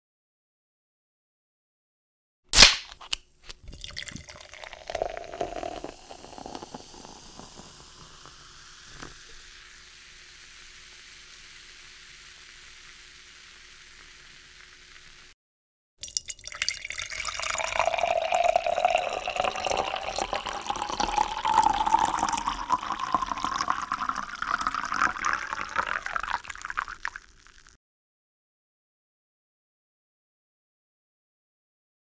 جلوه های صوتی
دانلود صدای بازکردن در قوطی نوشابه 1 از ساعد نیوز با لینک مستقیم و کیفیت بالا